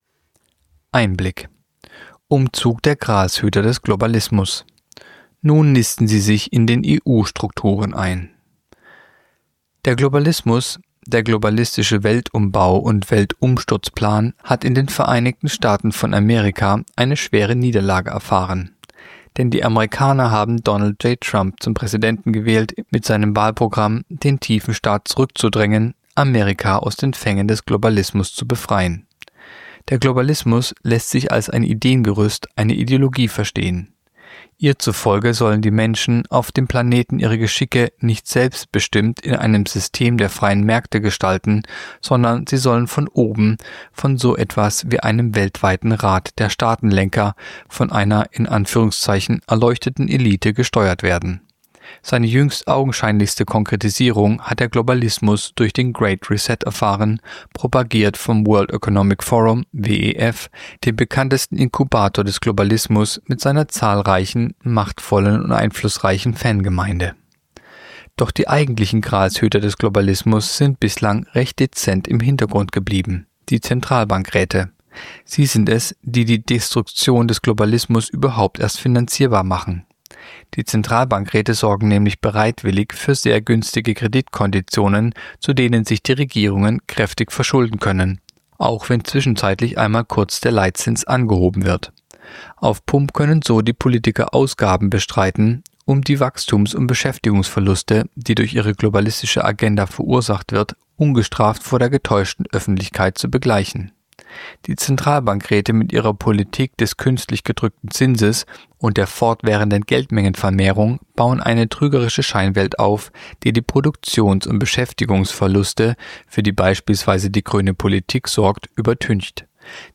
Artikel der Woche (Radio)Umzug der Gralshüter des Globalismus